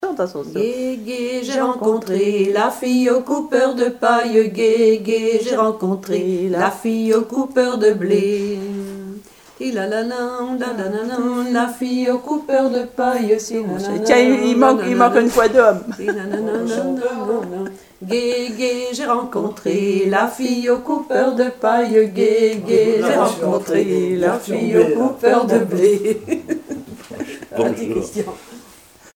Couplets à danser
Chansons et commentaires
Pièce musicale inédite